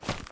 STEPS Dirt, Run 06.wav